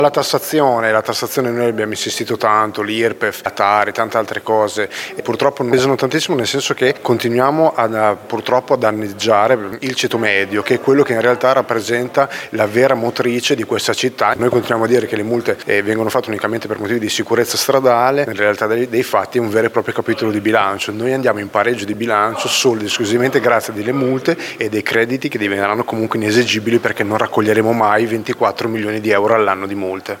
Critiche al bilancio per una mancata visione del futuro da parte di Piergiulio Giacobazzi, capogruppo di Forza Italia